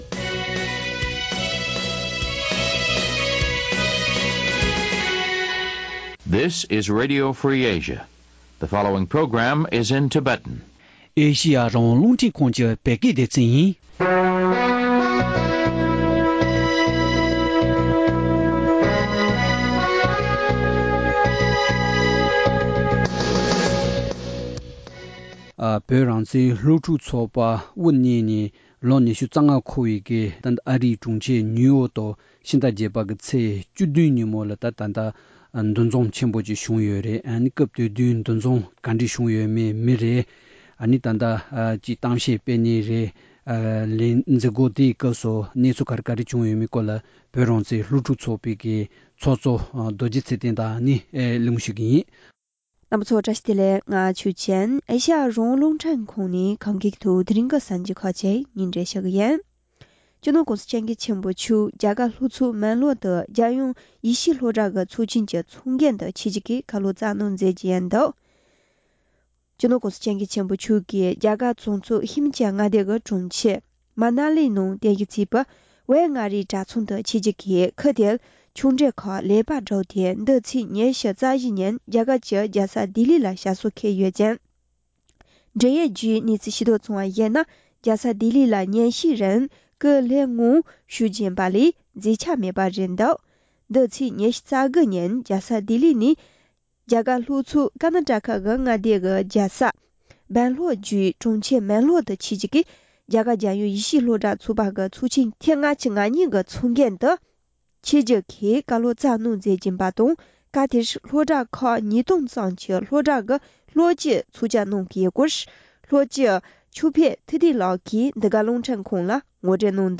བོད་རང་བཙན་སློབ་ཕྲུག་པའི་ལས་དོན་གནང་ཕྱོགས་དང་གྲུབ་འབྲས་སོགས་ཀྱི་ཐད་གླེང་མོལ།